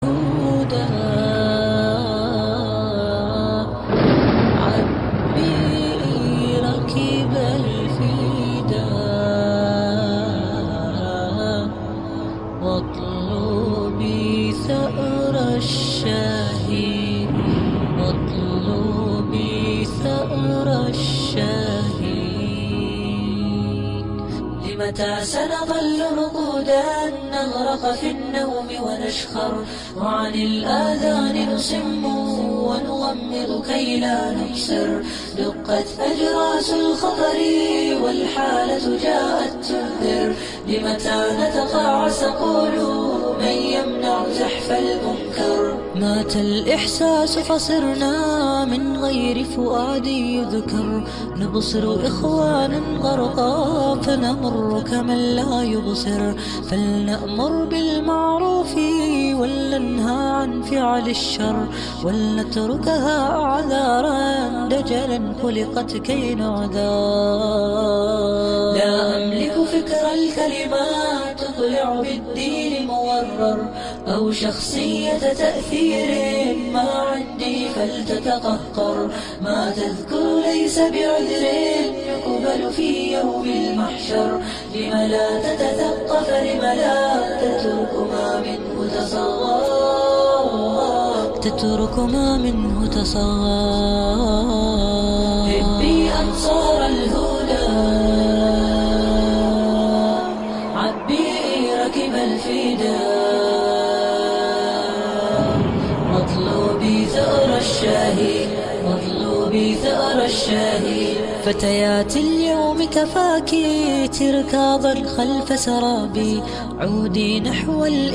• Качество: 128, Stereo
красивые
спокойные
медленные
Нашид
Вдохновляющий мусульманский нашид